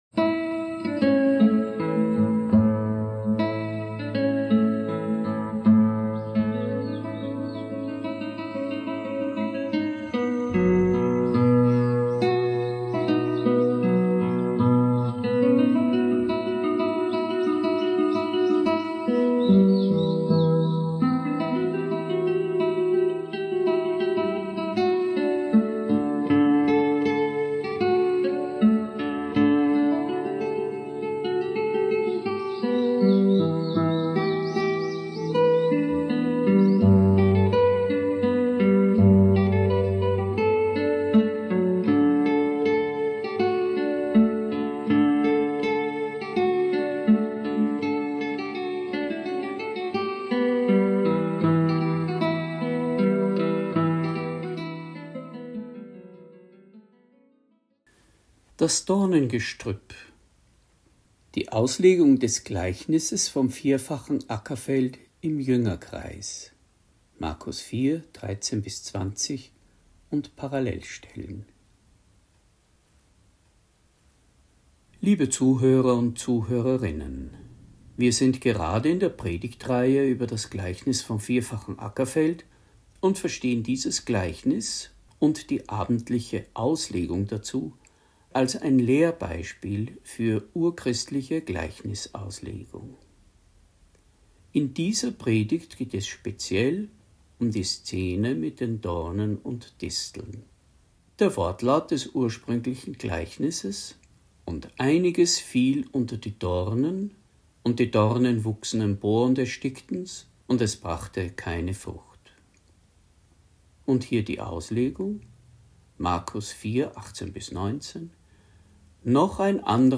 Predigt | NT02 Markus 4,13-20 Das 4-fache Ackerfeld (4) Dornen – Glauben und Leben